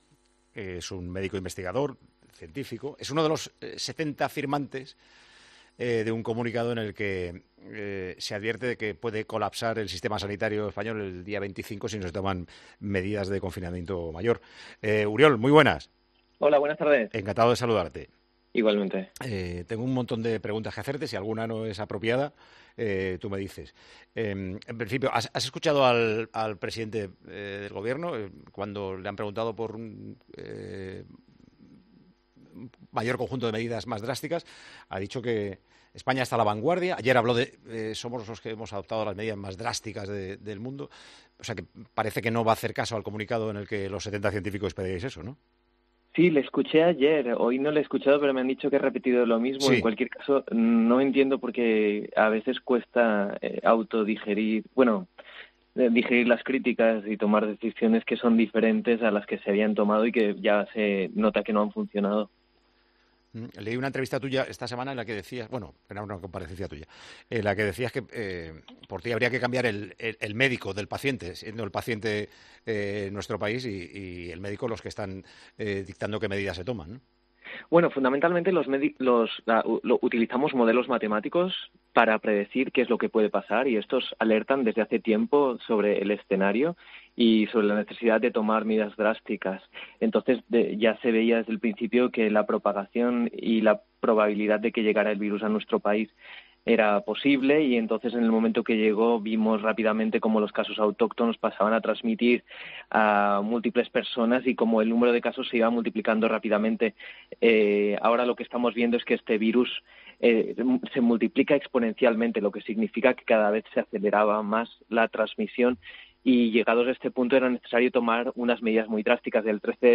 AUDIO: El médico investigador nos cuenta por qué ha pedido al Gobierno que aumente las medidas contra el coronavirus.